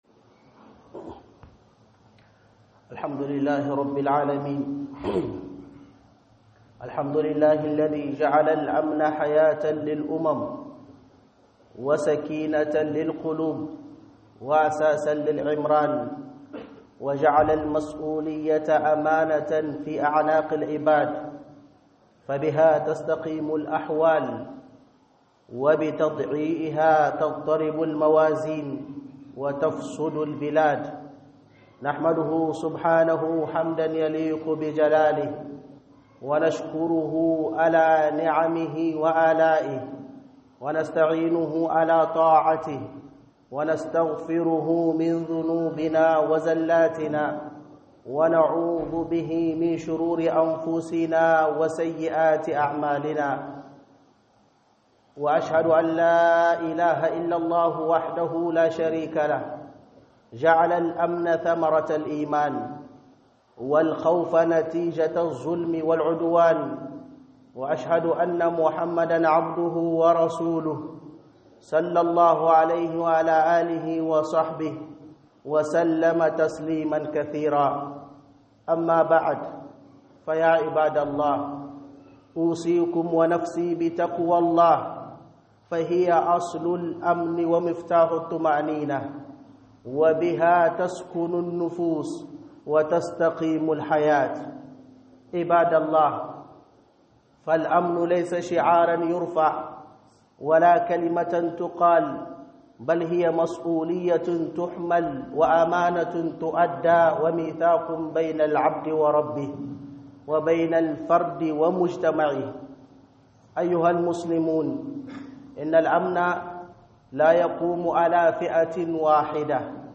Hudubah Aminci - HUƊUBAR JUMA'A